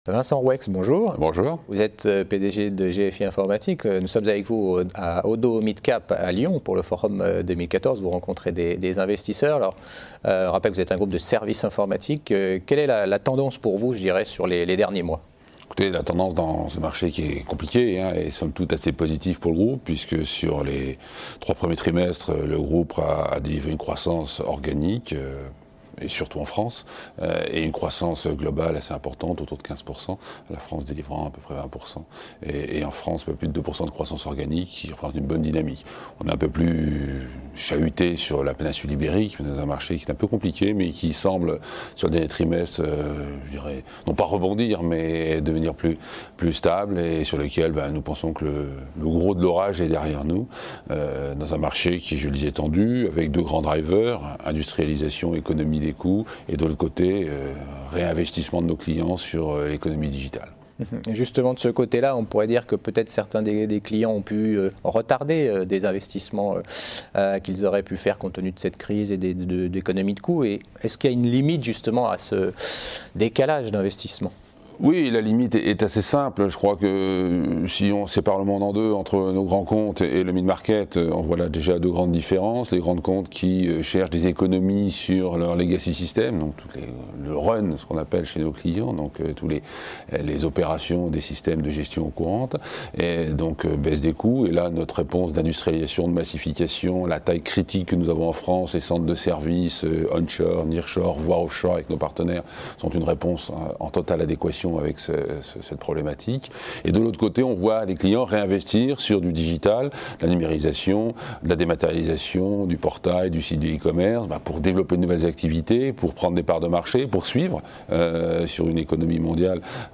Oddo Midcap Forum 2014 : Stratégie et perspectives de GFI Informatique.